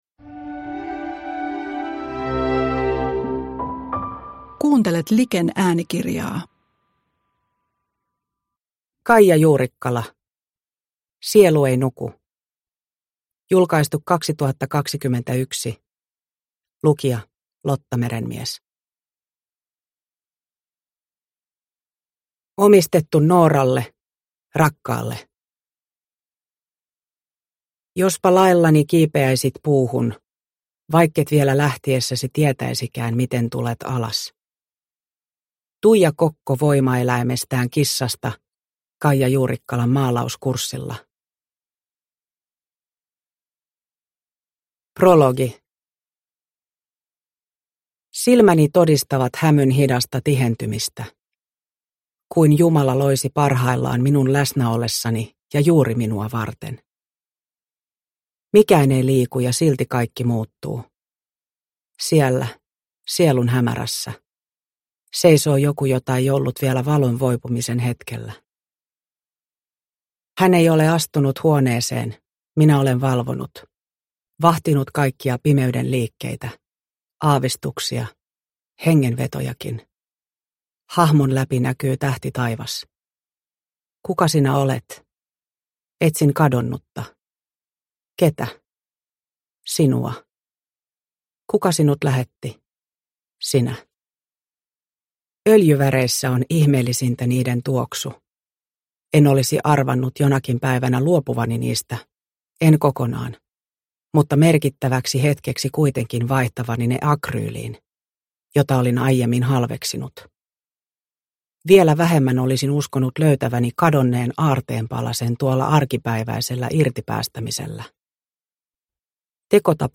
Sielu ei nuku – Ljudbok – Laddas ner